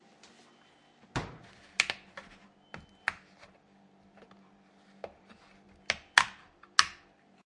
冰箱门打开关闭
描述：这是冰箱门打开和关闭的记录。用Sennheiser ME66和Tascam DR60D记录。